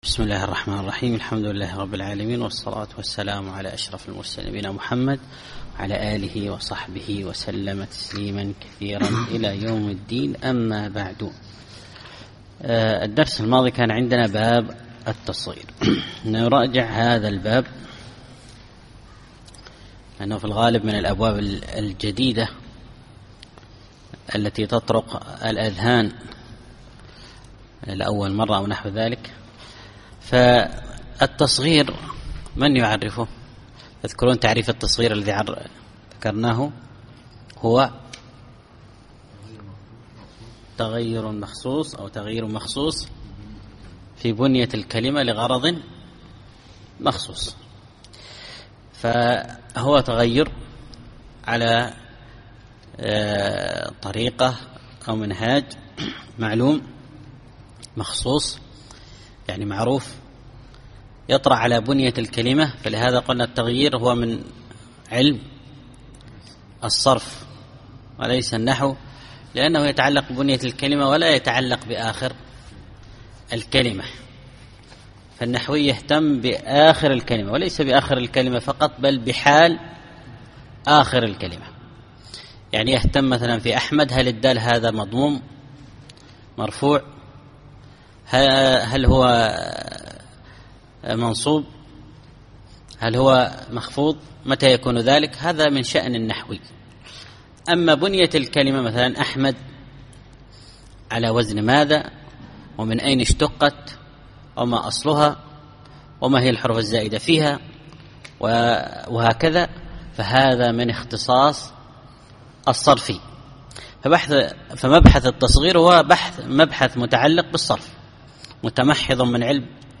الدرس الخامس والعشرون الأبيات 268-276